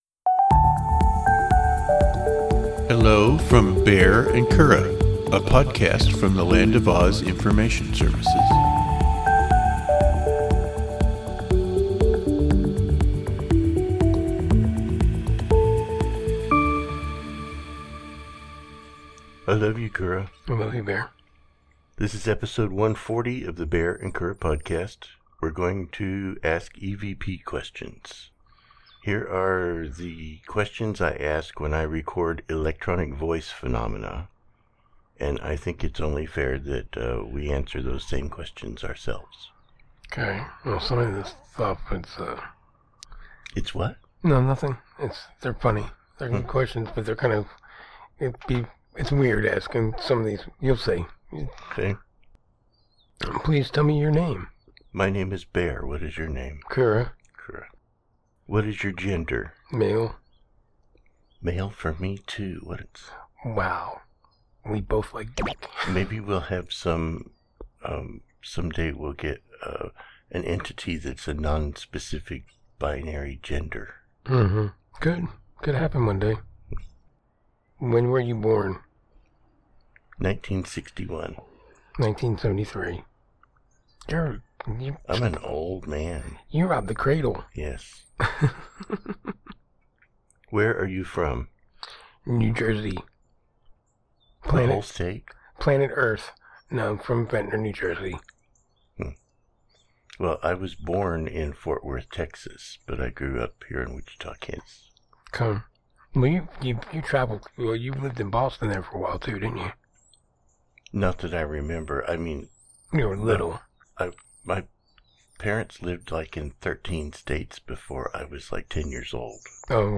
Two married gay guys discuss life, synergy, and the pursuit of happiness.